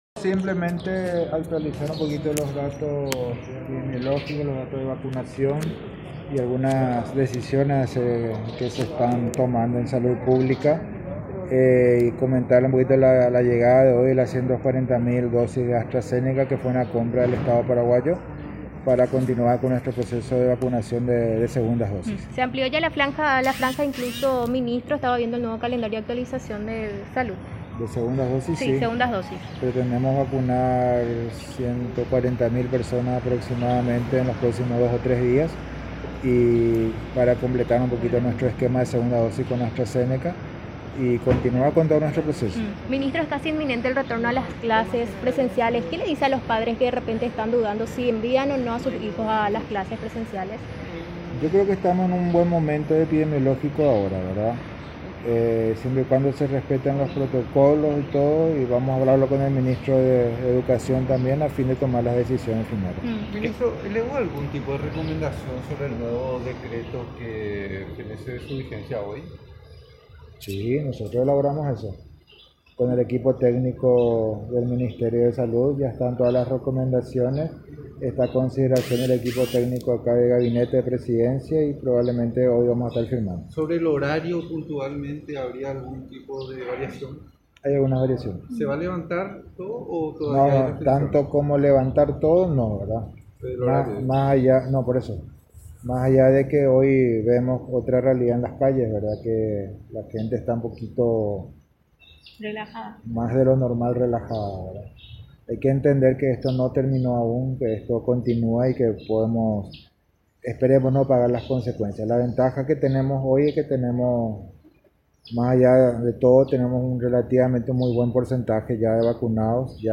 “Ya elaboramos el nuevo decreto con el equipo técnico del Ministerio de Salud. Ya están todas las recomendaciones y las dejamos a consideración del Gabinete de la Presidencia”, dijo Borba en diálogo con los medios de prensa, anticipando que se producirá una variación en el horario de circulación, que por el decreto que fenece este martes el horario restringido es de 01:00 a 05:00 de lunes a domingo.
22-CONFERENCIA-DE-MINISTRO-JULIO-BORBA.mp3